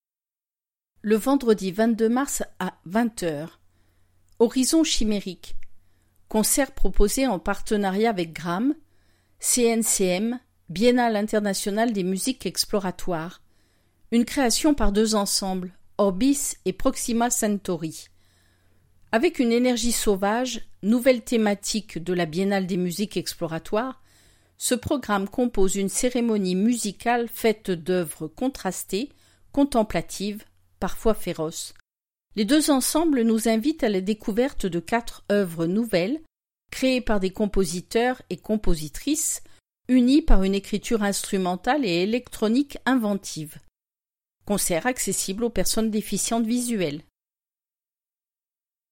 Présentation audio pour les personnes déficientes visuelles